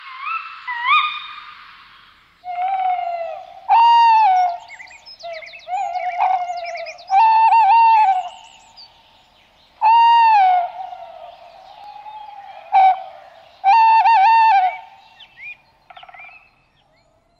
Waldkauz
Waldkauz-fertig.mp3